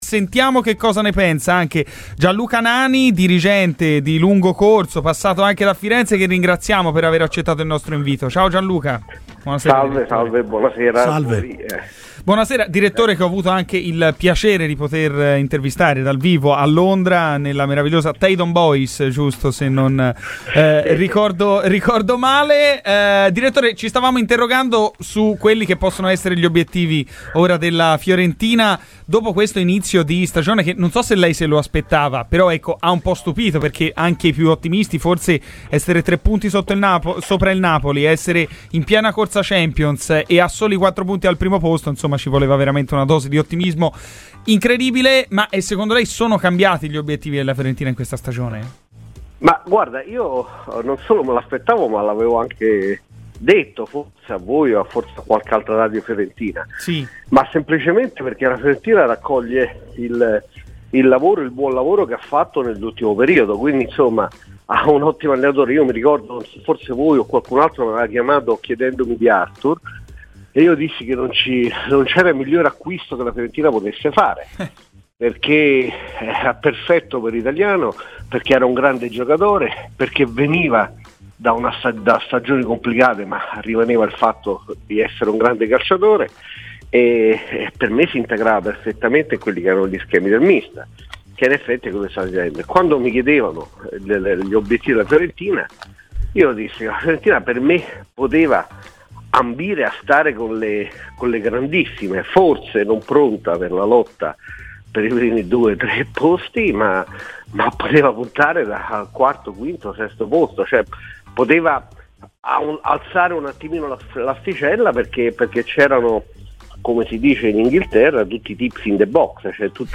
ha parlato ai microfoni di Radio FirenzeViola